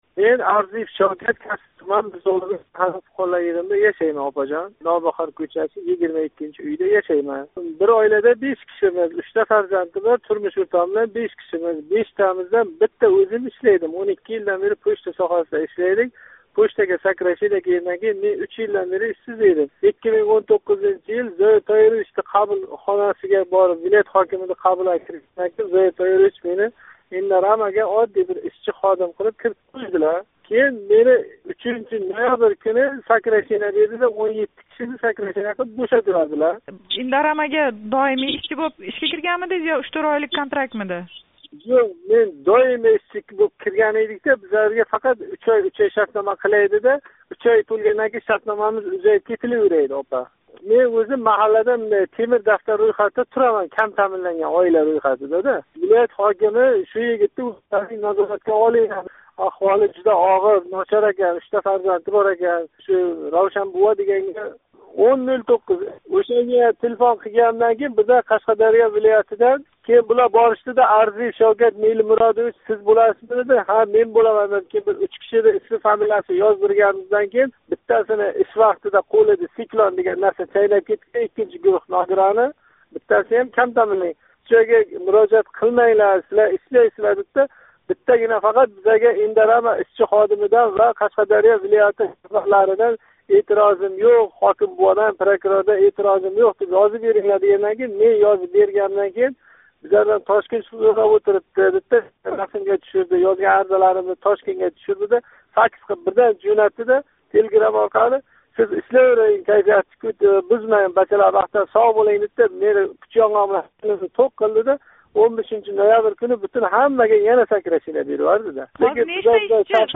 Indorama Agro МЧЖнинг Касби туманидаги ишчилари билан суҳбат